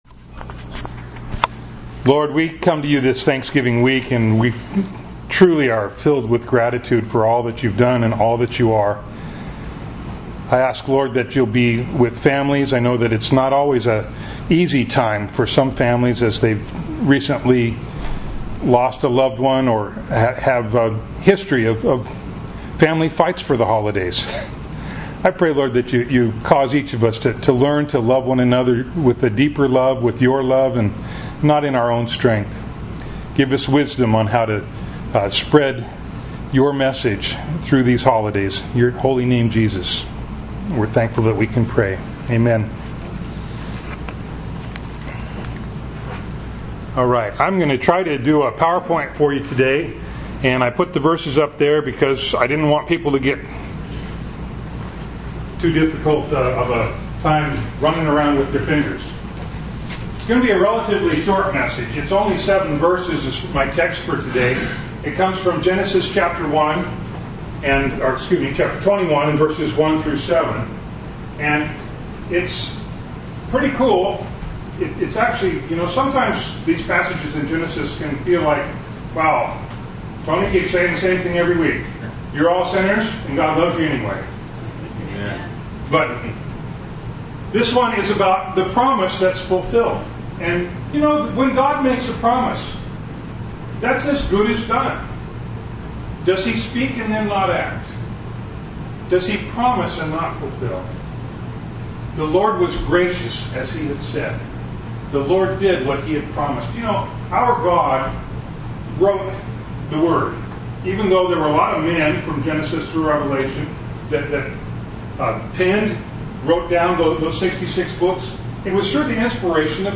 Bible Text: Genesis 21:1-7 | Preacher